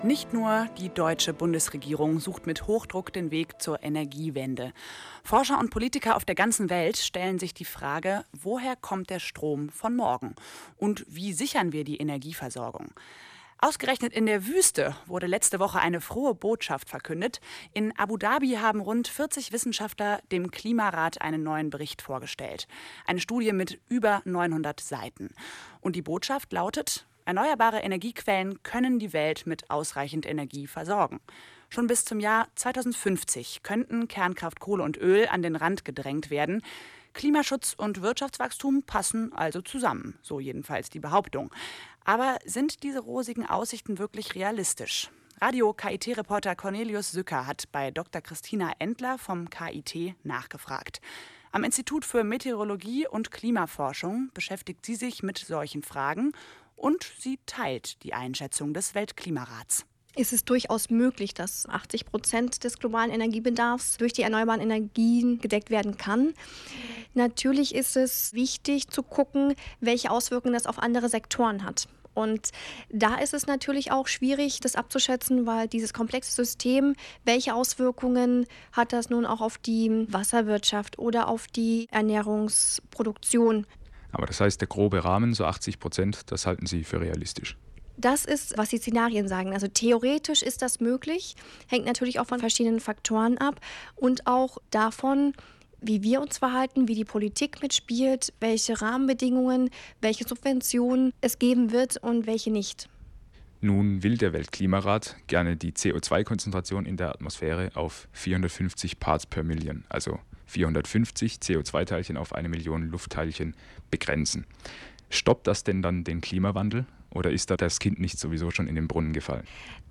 Ist der Umstieg auf erneuerbare Energien bis 2050 möglich? : Gespräch